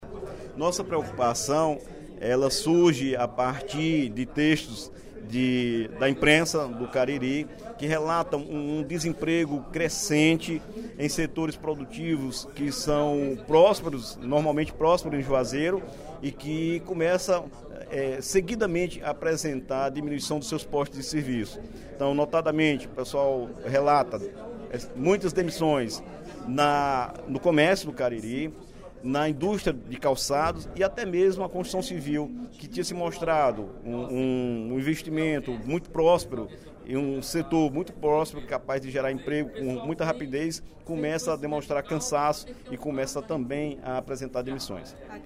O deputado Dr. Santana (PT) demonstrou preocupação, durante o primeiro expediente da sessão plenária da Assembleia Legislativa desta quinta-feira (09/03), com o desemprego crescente na região do Cariri, especificamente em Juazeiro do Norte.